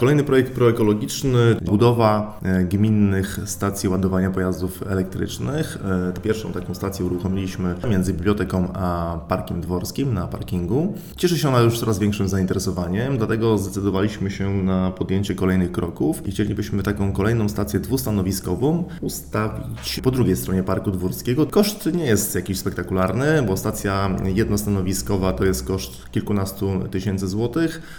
mówi szef magistratu Paweł Lichtański